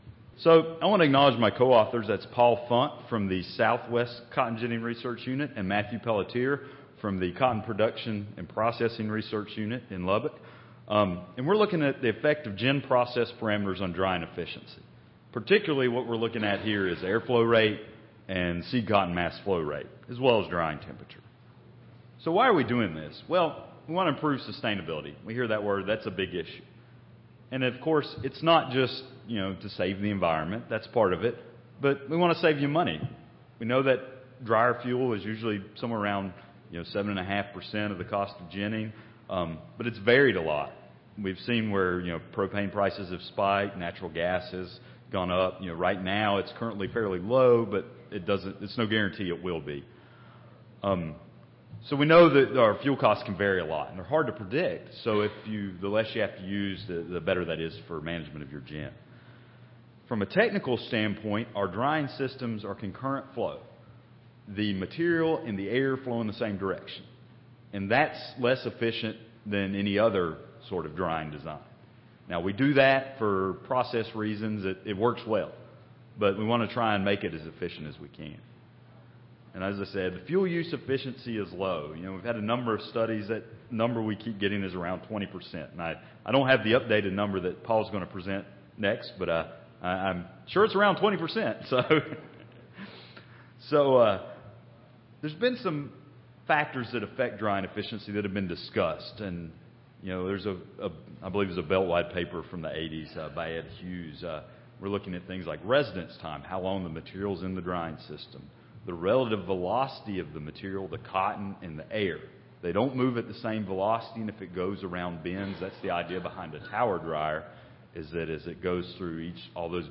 Recorded Presentation A test was conducted to determine the effect of air flow rate, seed cotton feed rate, drying temperature, and seed cotton moisture content on the drying efficiency of a tower drying system in a gin.